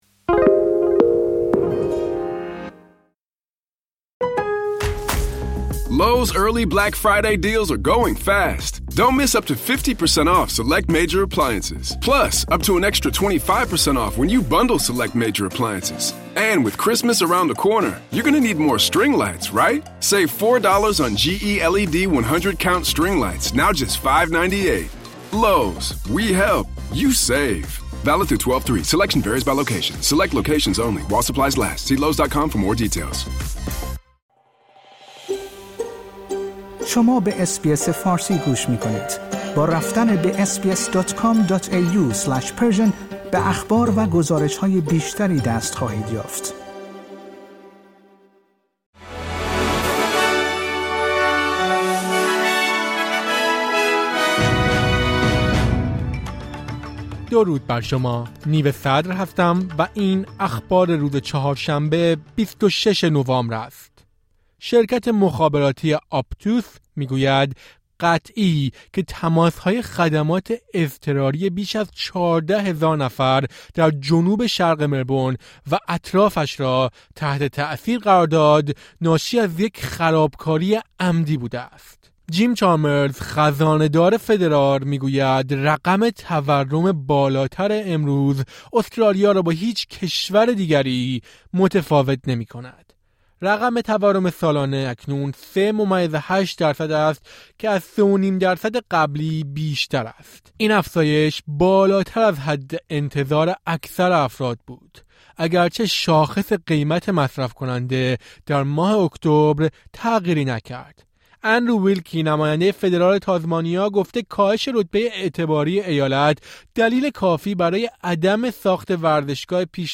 در این پادکست خبری مهمترین اخبار روز چهارشنبه ۲۶ نوامبر ارائه شده است.